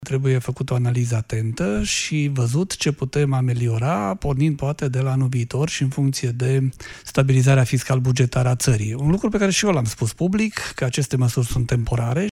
Prezent la emisiunea Academia Europa FM, David a făcut apel la calm.